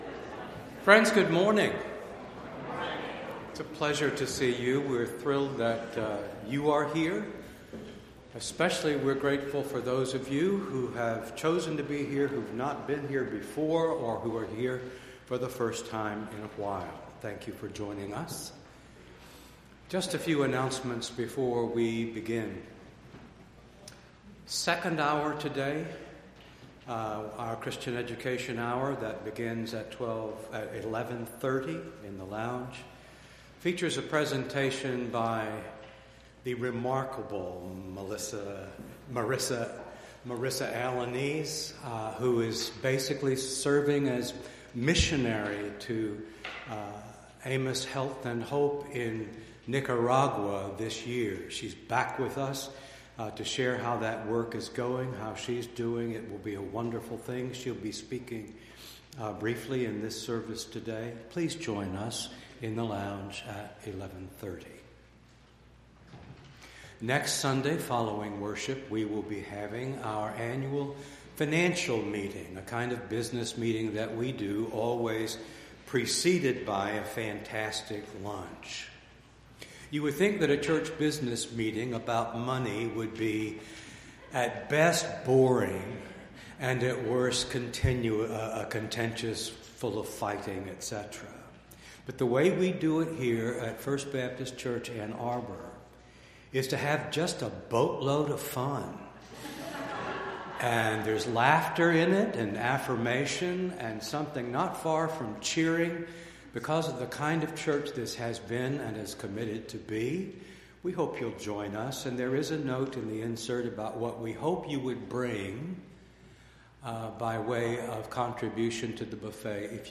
Entire January 13th Service